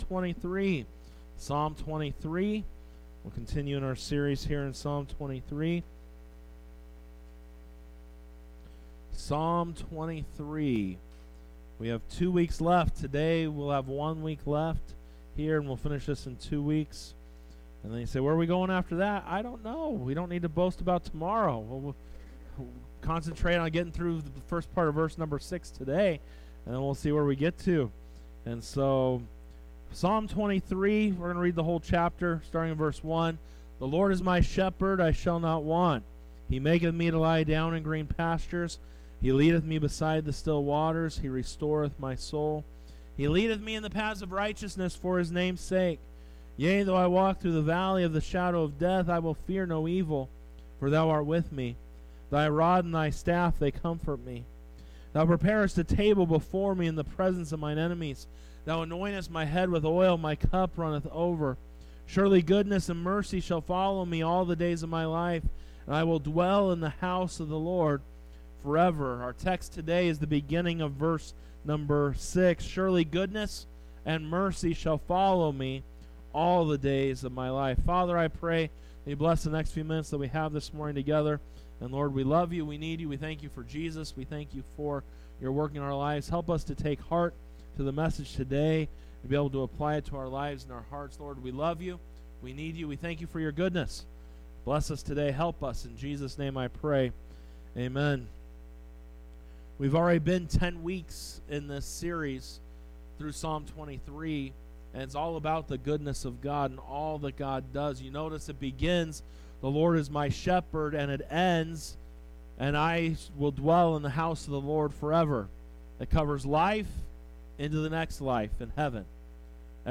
Sunday Worship Service 07:21:24 - Why You Don't Need...